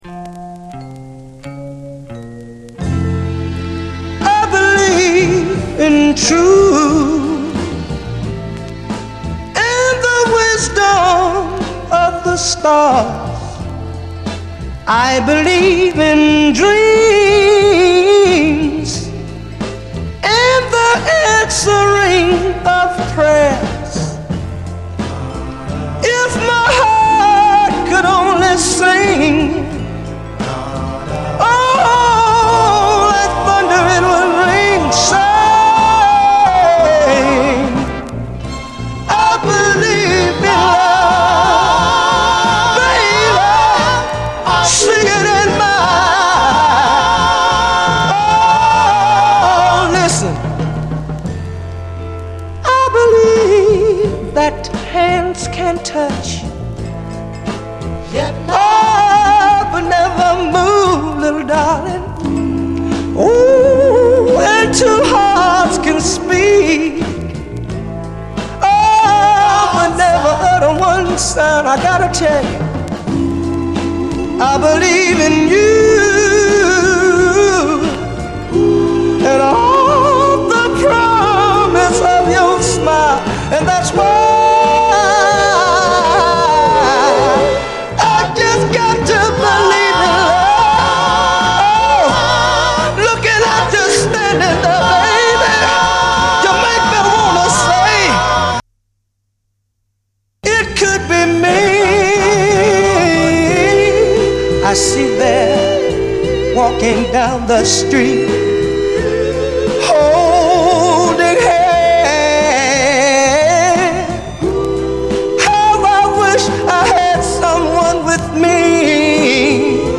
SOUL, 60's SOUL
クリーヴランド出身60'Sソウル・ヴォーカル・グループ！
スウィートなコーラス＆ヴォーカルが咽び泣くローライダー・ソウル